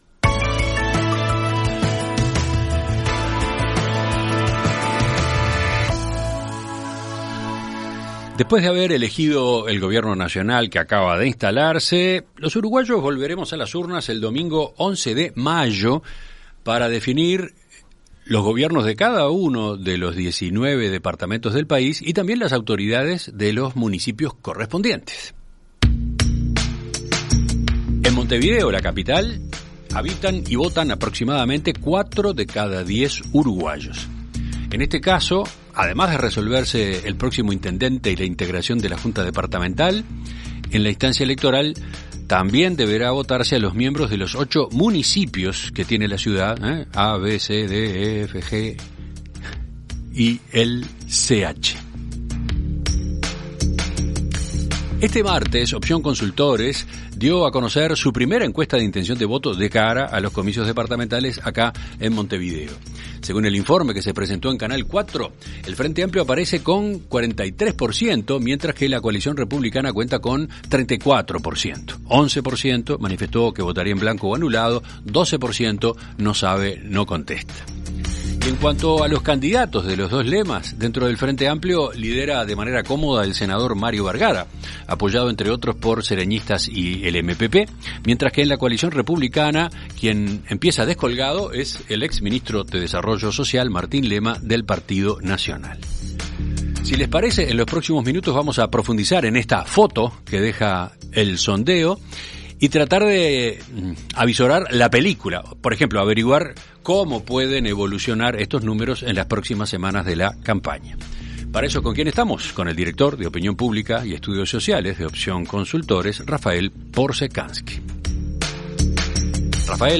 Entrevista Central